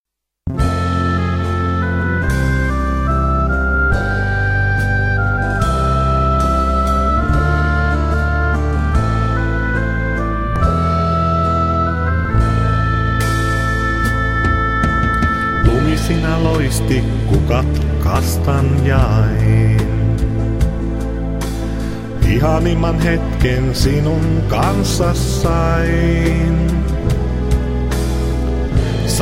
perinteistä tanssimusiikkia
Rummut, laulu
Kitara, laulu
Kosketinsoittimet, haitari, laulu